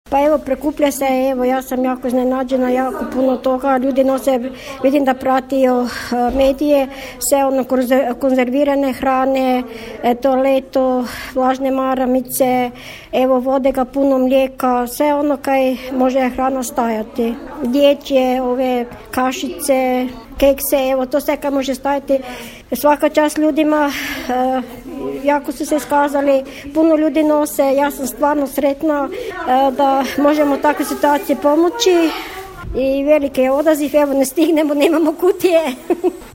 Prelog, prikupljanje potrepština za stradale u potresu, 30.12.2020.